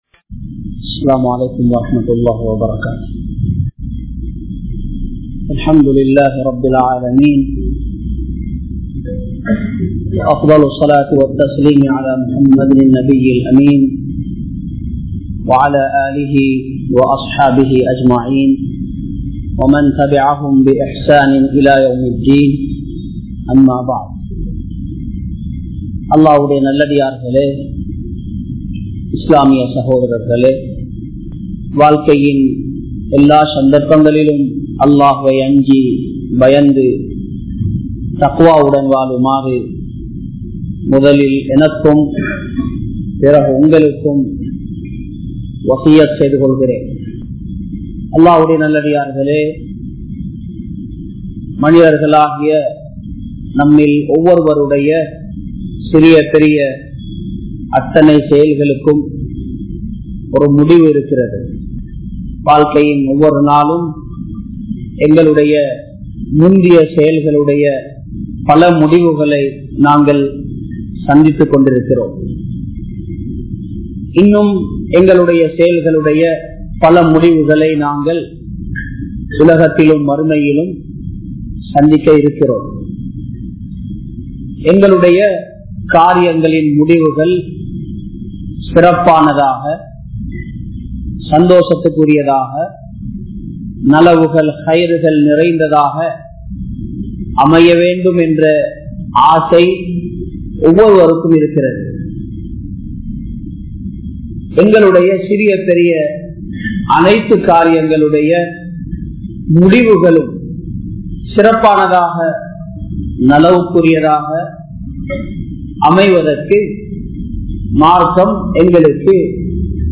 Isthiqarah & Sajadha Shuqoor (இஸ்திகாரா & ஸஜ்தா ஸூகூர்) | Audio Bayans | All Ceylon Muslim Youth Community | Addalaichenai
Thaqwa Jumua Masjith